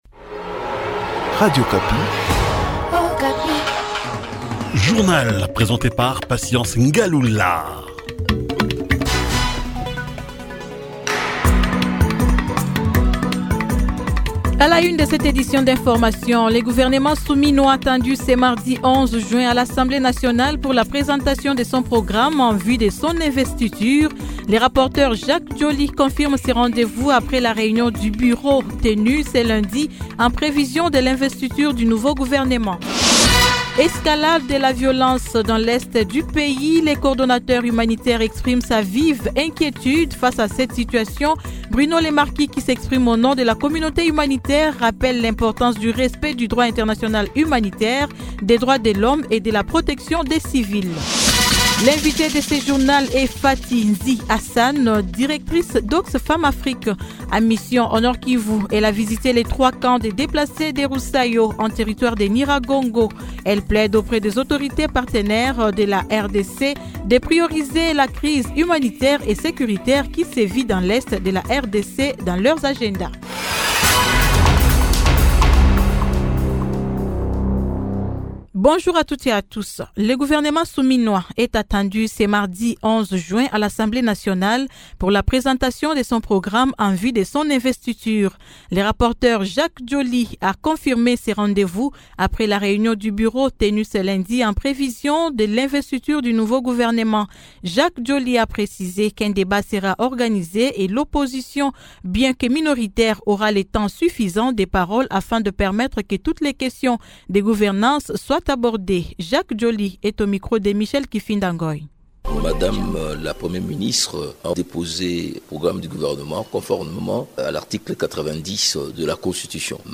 Journal matin 08H